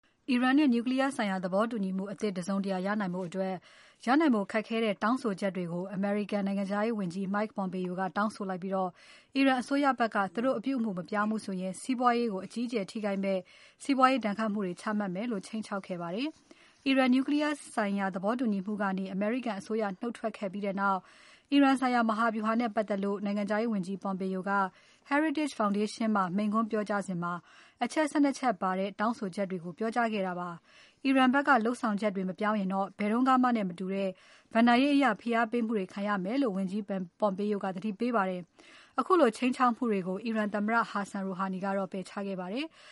အီရန်နဲ့ နျူကလီးယား သဘောတူညီမှုကနေ အမေရိကန်အစိုးရ နုတ်ထွက်ခဲ့ပြီးတဲ့နောက် အီရန်ဆိုင်ရာ မဟာဗျူဟာနဲ့ ပတ်သက်လို့ အမေရိကန်နိုင်ငံခြားရေးဝန်ကြီး Pompeo က heritage foundation မှာ မိန့်ခွန်းပြောကြားစဉ် အချက် ၁၂ ချက်ပါတဲ့ တောင်းဆိုချက်တွေကို ပြောကြားခဲ့ပါတယ်။